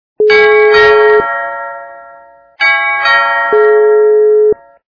При прослушивании Дверной - звонок качество понижено и присутствуют гудки.
Звук Дверной - звонок